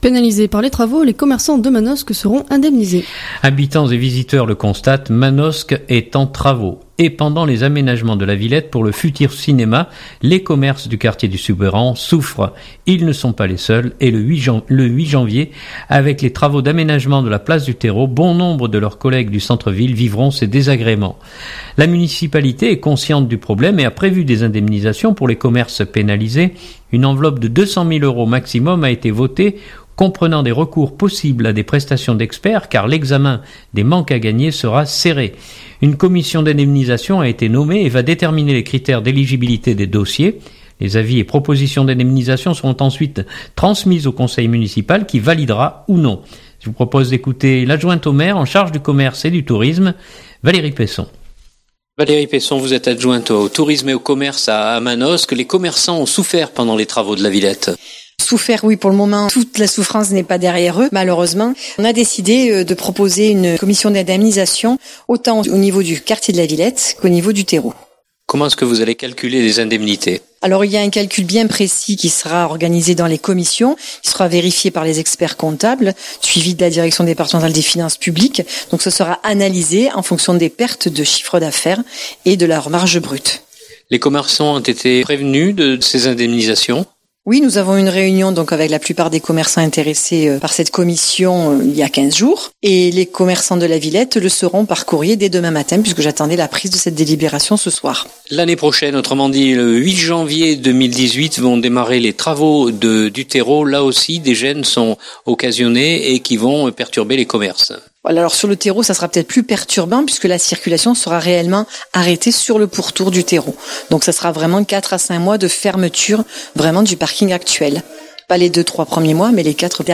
Je vous propose d’écouter l’adjointe au maire en charge du commerce et du tourisme Valérie Peisson. écouter: 2017-12-19 - Manosque - Commerçants Indemnisés.mp3 (1.28 Mo)